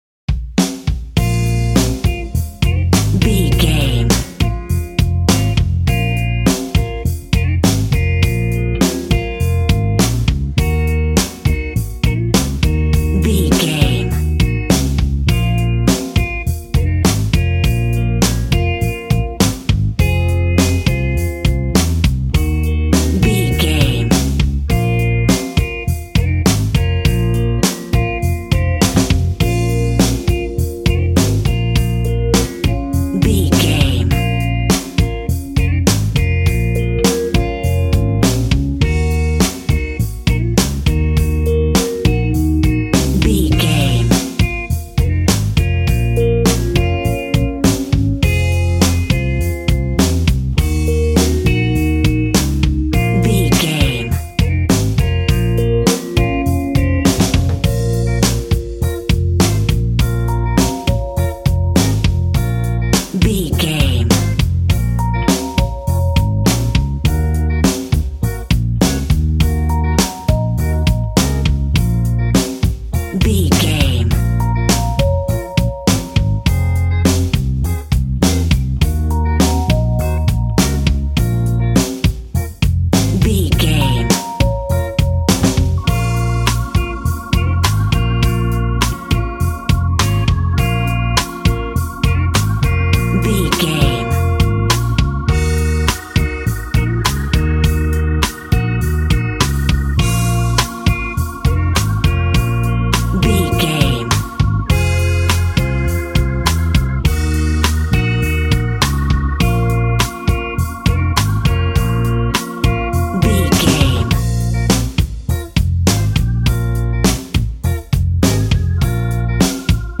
Aeolian/Minor
B♭
sad
mournful
bass guitar
electric guitar
electric organ
drums